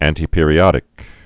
(ăntē-pîrē-ŏdĭk, ăntī-)